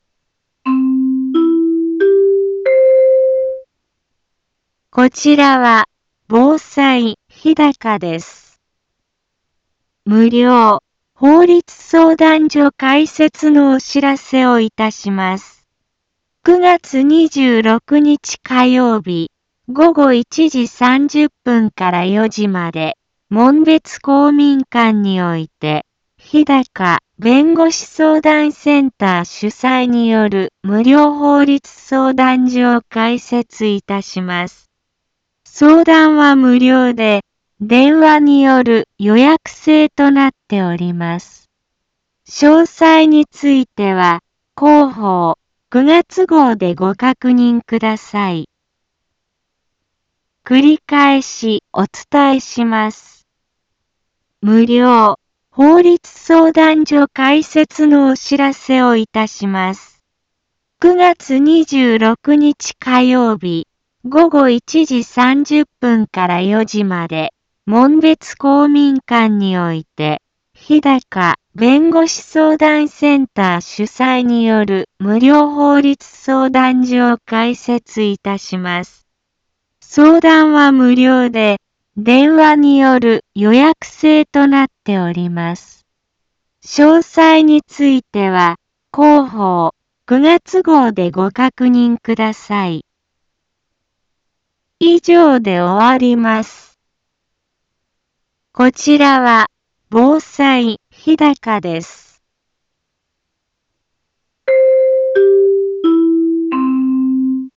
Back Home 一般放送情報 音声放送 再生 一般放送情報 登録日時：2023-09-22 15:03:47 タイトル：無料法律相談会のお知らせ インフォメーション： 無料法律相談所開設のお知らせをいたします。 9月26日火曜日午後1時30分から4時まで、門別公民館において、ひだか弁護士相談センター主催による無料法律相談所を開設いたします。